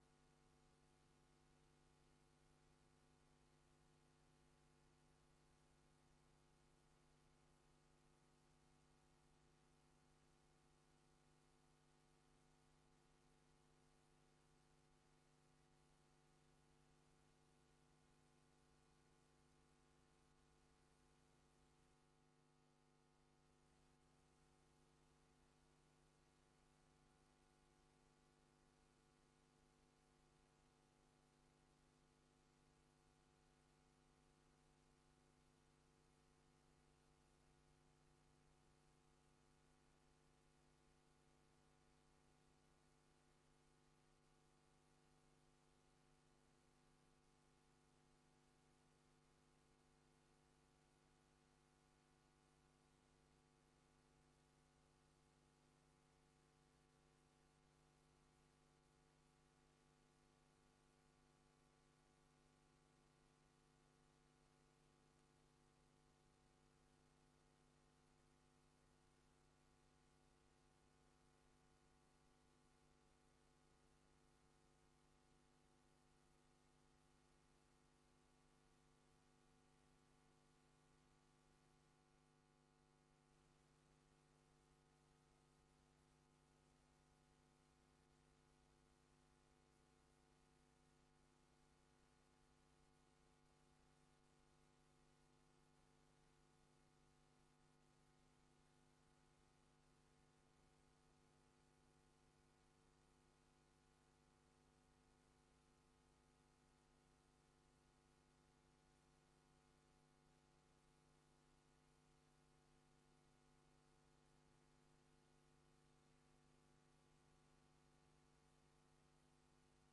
Raadsbijeenkomst 25 juni 2024 20:00:00, Gemeente Tynaarlo